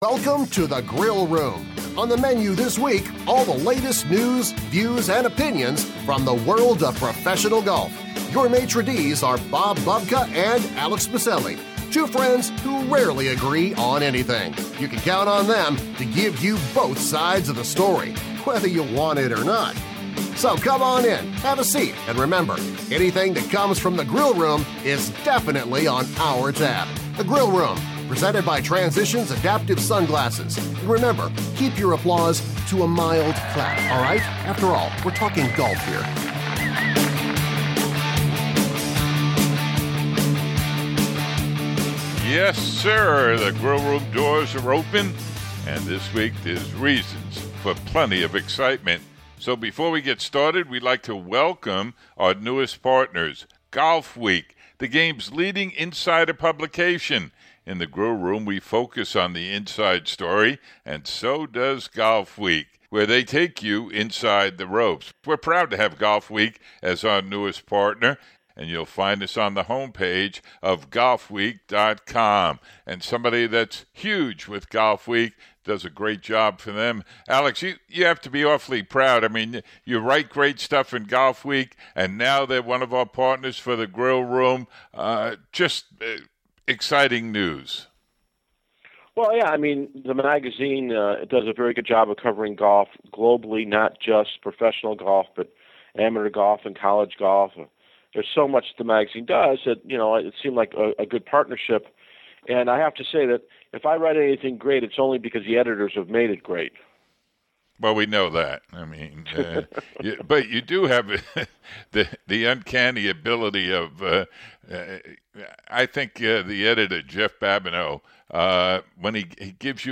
Clark & Langer talk about their victories